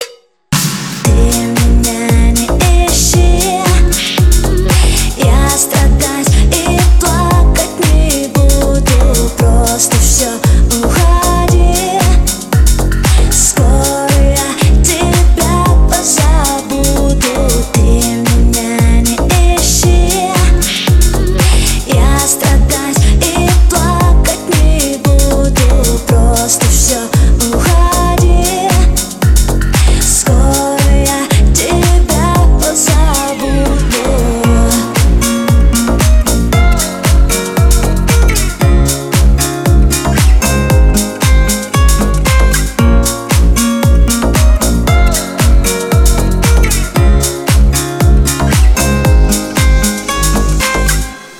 • Качество: 320, Stereo
гитара
deep house
remastered